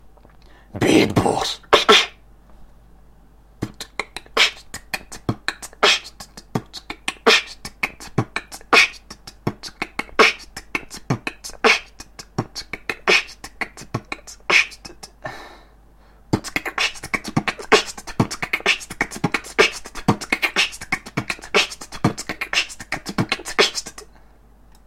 Два простеньких хип-хоп бита
b t k k | kch t t k | t b k t | kch t t t
ее круто smile ток второй биток как то в ритм не входит))